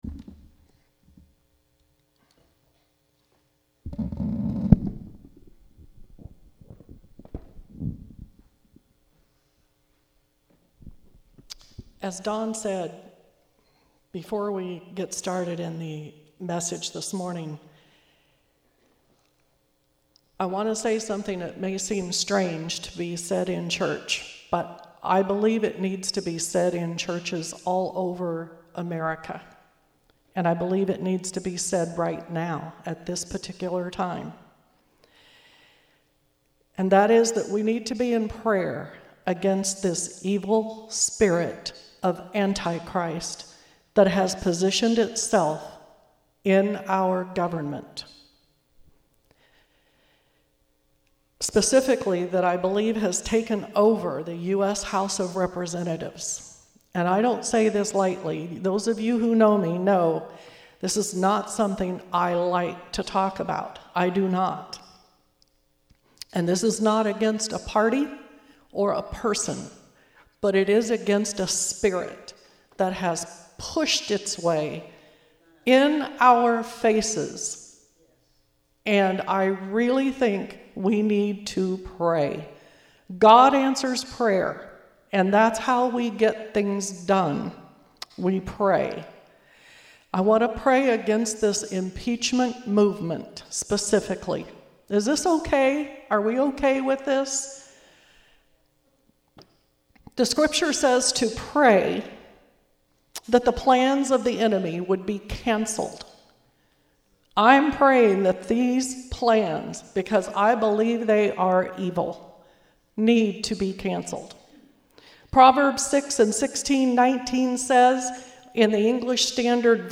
Morning Sermons